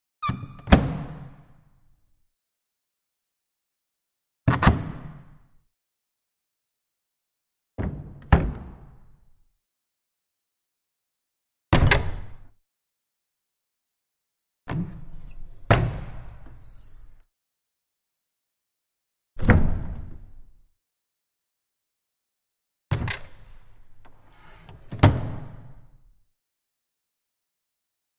Cabinet With Slight Squeak; Open And Close, Small Stone Reverberant Interior